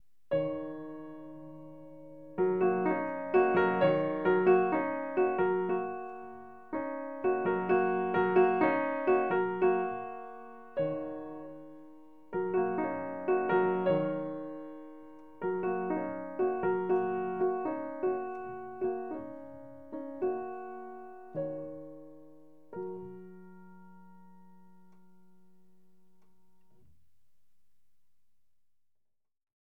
Solos piano